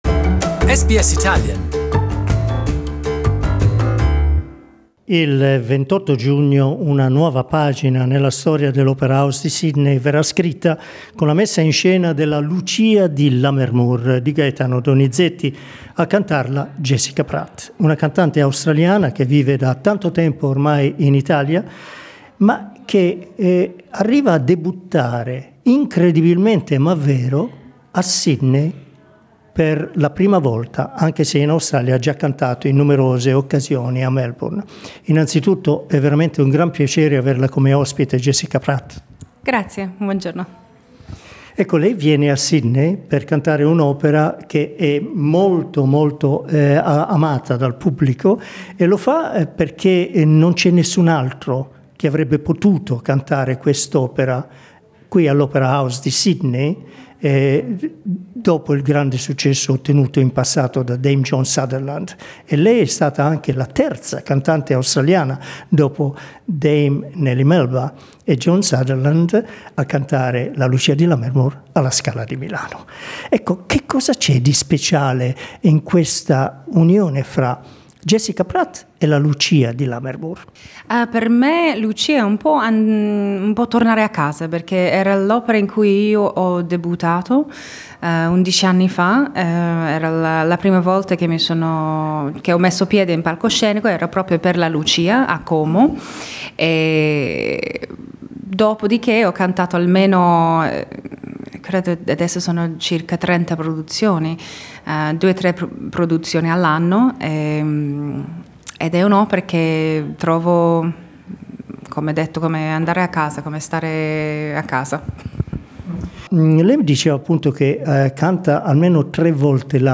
In this interview Jessica Pratt tells us what kind of Lucia we can expect when she steps on the Joan Sutherland theatre stage on 28th June for her Sydney's debut.